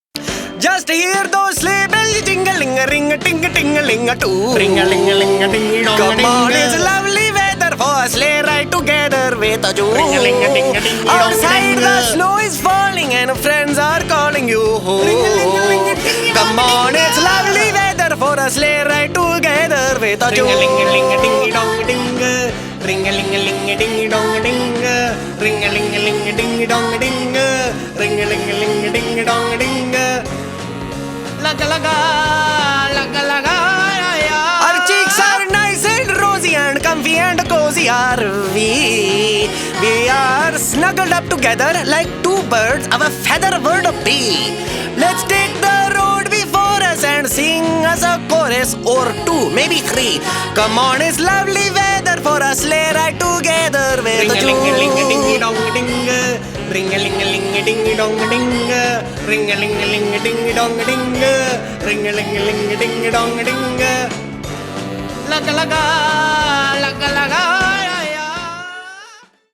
с добавлением индийских музыкальных элементов